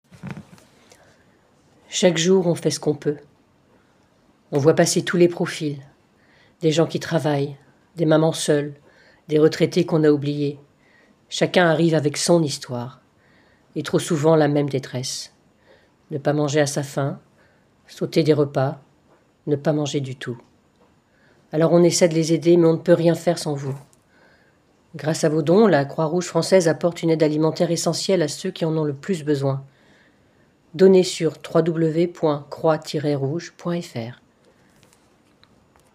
Voix off
43 - 57 ans - Soprano